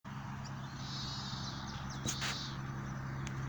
White-tipped Plantcutter (Phytotoma rutila)
Sex: Male
Life Stage: Adult
Country: Argentina
Condition: Wild
Certainty: Photographed, Recorded vocal